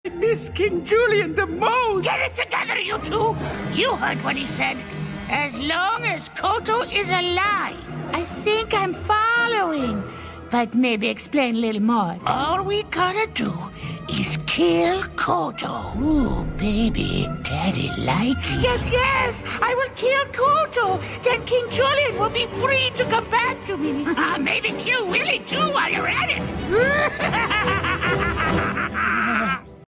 Haha Sound Effects Free Download